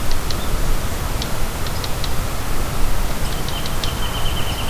Sounds of the Escondido Creek Watershed
Woodpecker Pecking
Woodpecker Pecking - edited.wav